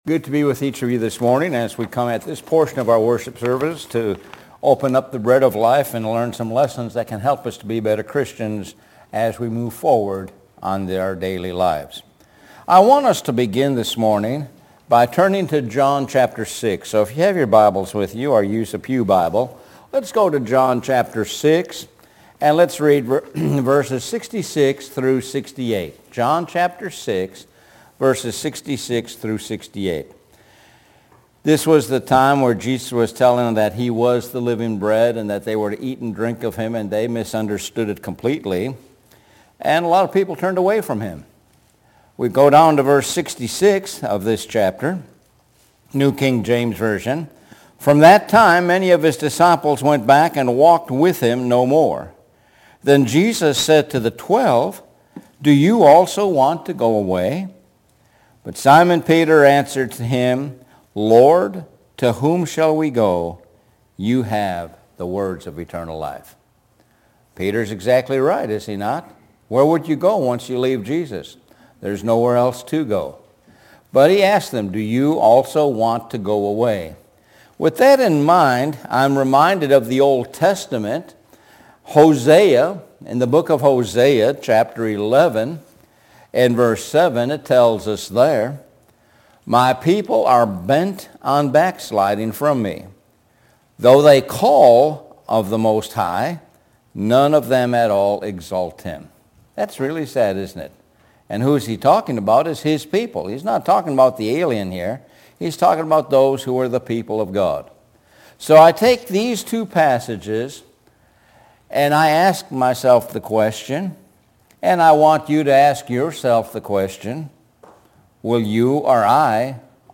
Sun AM Sermon – Will You Also go Away – 2.16.2025